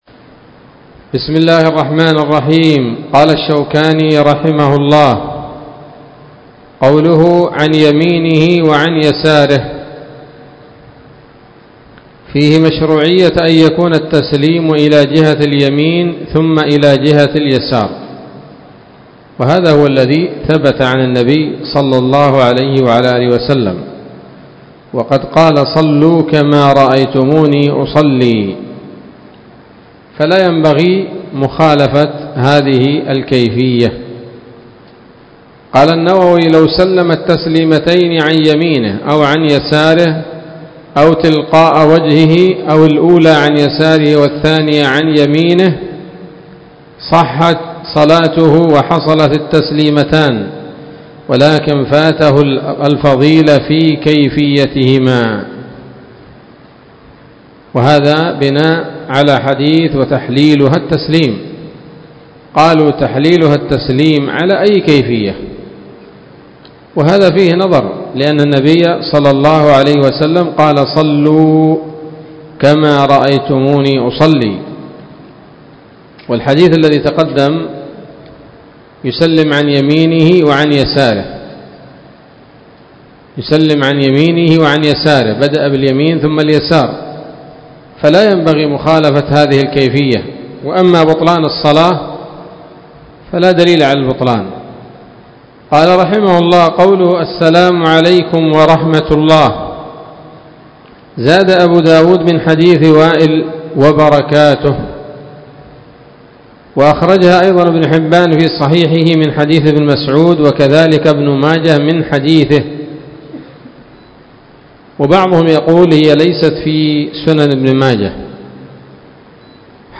08 - أبواب صفة الصلاة الدروس العلمية شروح الفقه كتاب الصلاة نيل الأوطار